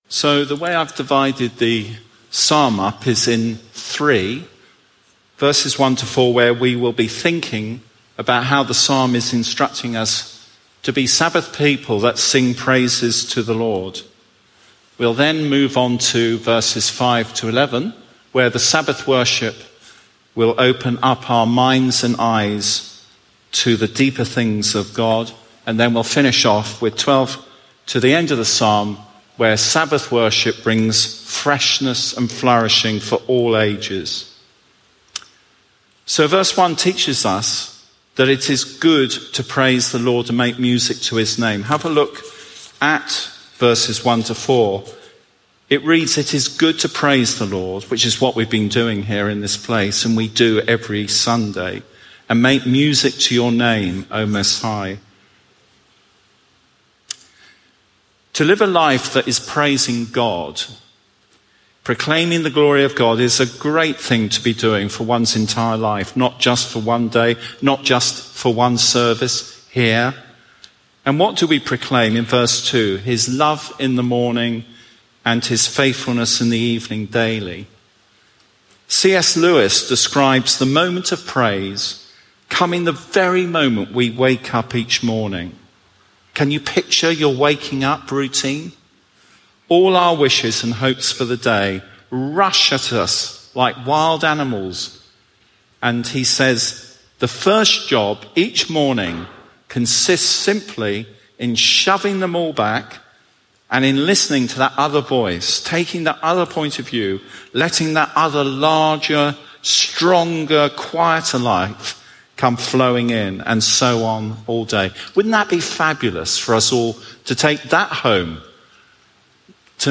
Sunday sermon feed from All Souls, Langham Place